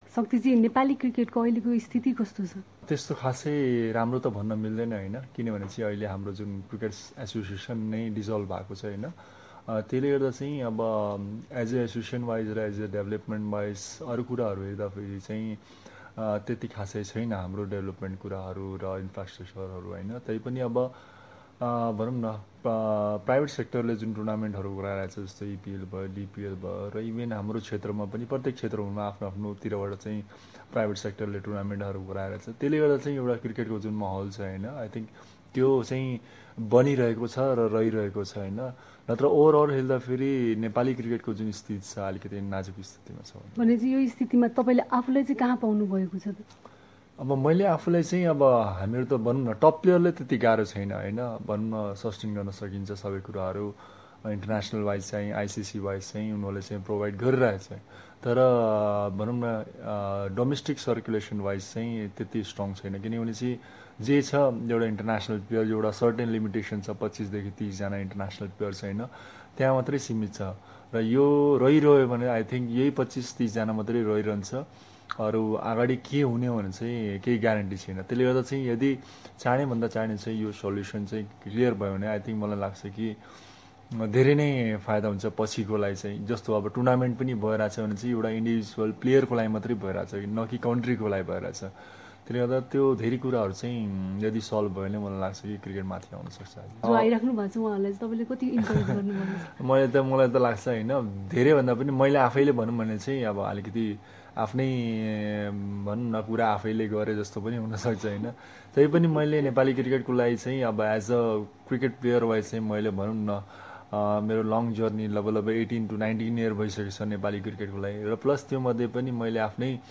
Nepali Cricketer Shakti Gauchan speaking to SBS Nepali.